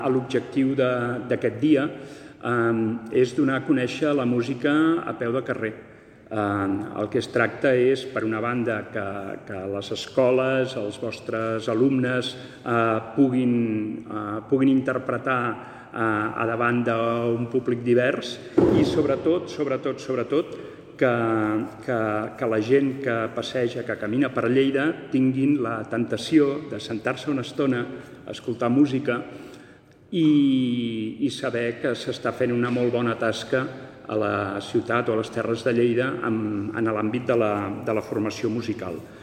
(Tall de veu J. Rutllant) La Festa de la Música és una celebració internacional que té lloc el dia 21 de juny, el primer dia de l’estiu a l’hemisferi nord (el dia més llarg de l’any).
tall-de-veu-del-regidor-jaume-rutllant-sobre-la-15a-festa-de-la-musica